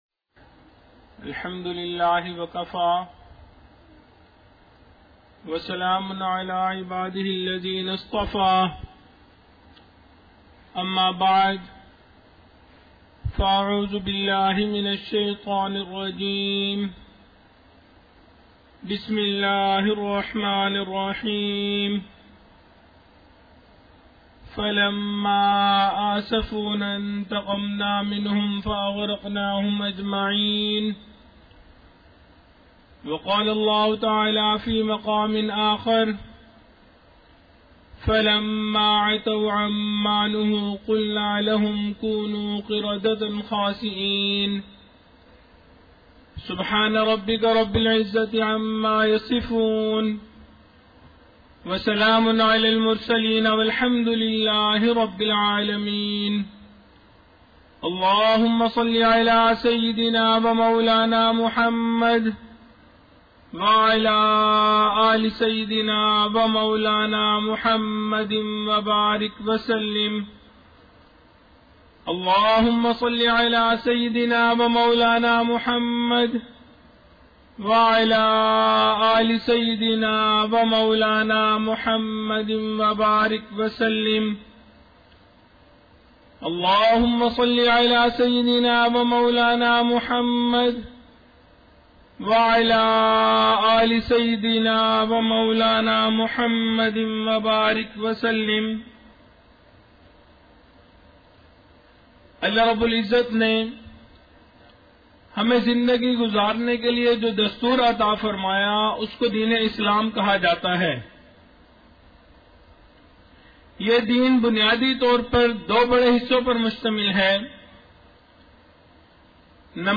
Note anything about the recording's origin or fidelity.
23 June 2017 | Mahad-ul-Faqeer, Jhang | 41m 22s